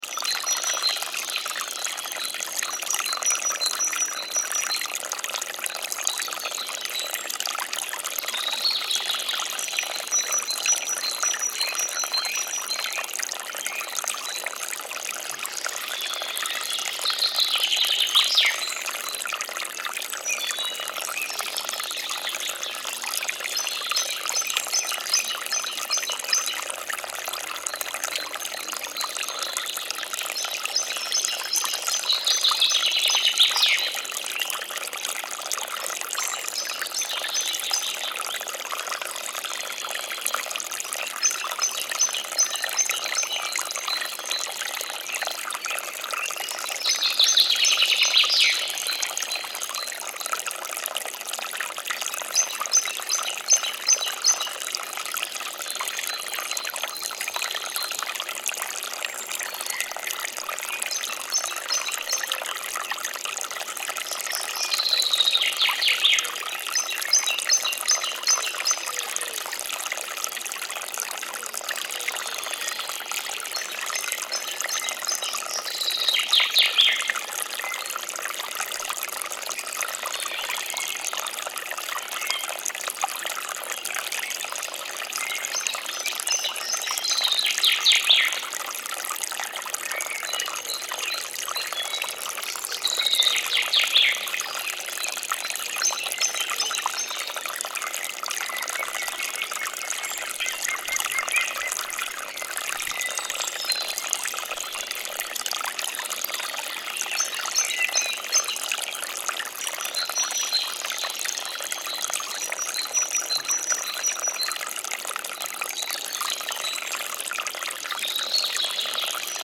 Forest Creek With Birds Singing Sound Effect
A small forest creek babbles in spring while birds chirp in the background. Relaxing sounds. Audio loop.
Forest-creek-with-birds-singing-sound-effect.mp3